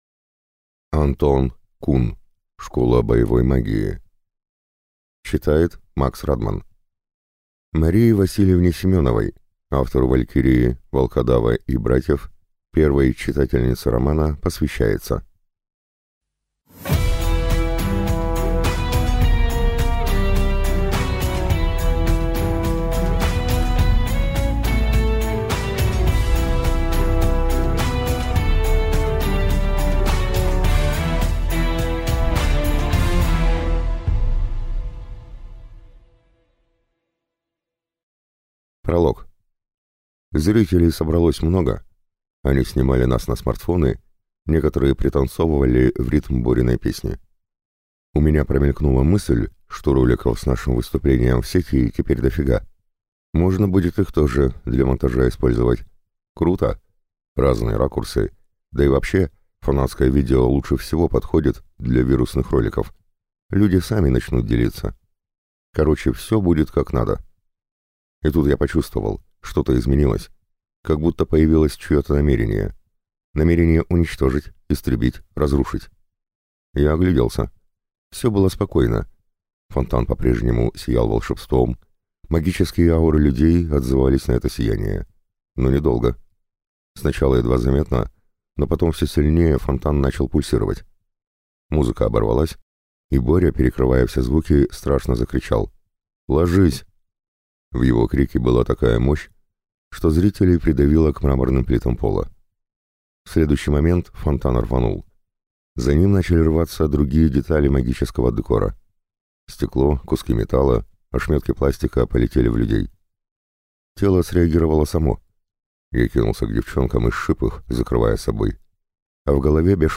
Слушать аудиокнигу Коллекционер полностью